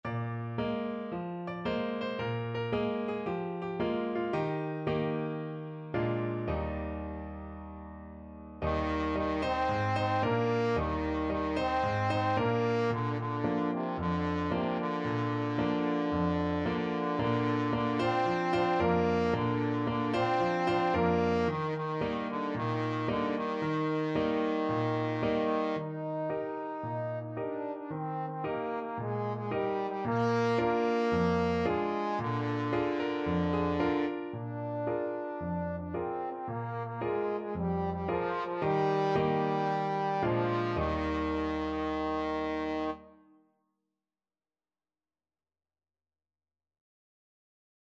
Trombone version
4/4 (View more 4/4 Music)
Cheerfully! =c.112
Traditional (View more Traditional Trombone Music)